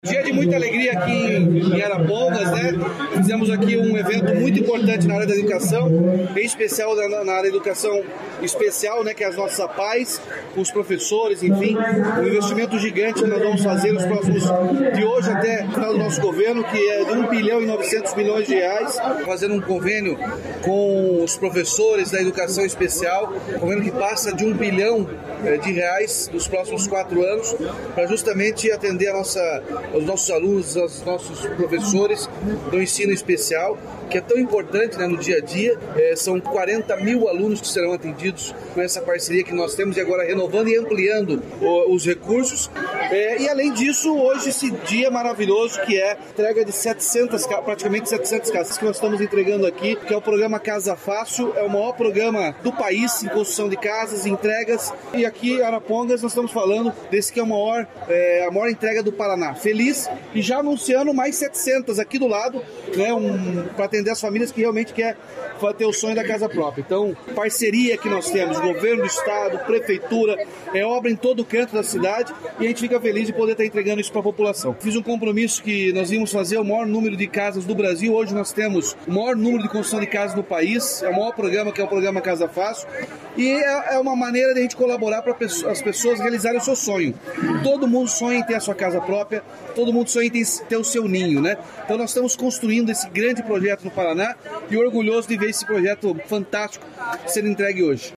Sonora do governador Ratinho Junior sobre o novo convênio com as Apaes e sobre a entrega de casas em Arapongas